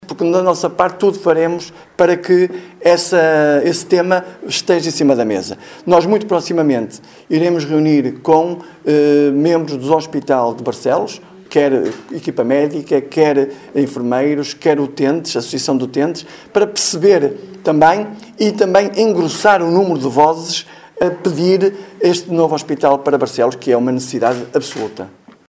A cooperação entre os dois municípios faz engrossar a voz em defesa de projectos como o novo Hospital de Barcelos, sublinha o autarca barcelense, Mário Constantino: